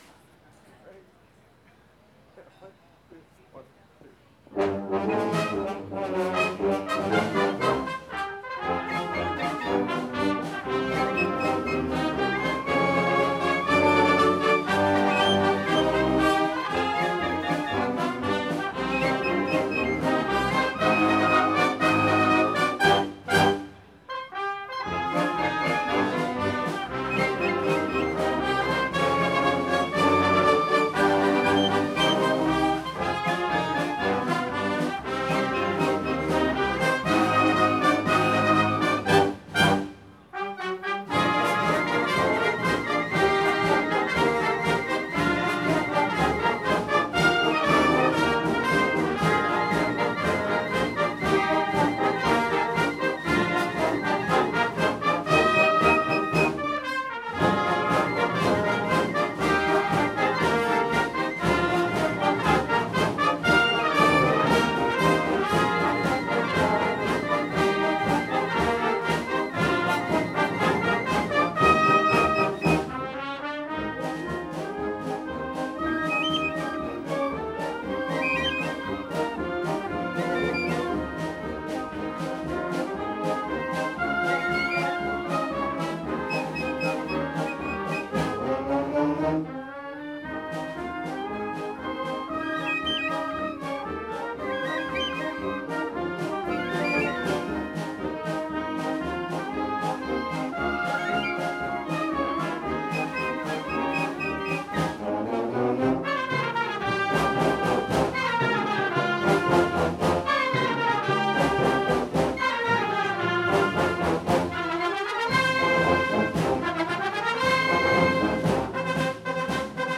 Listing of music recorded by Long Beach Community Band groups.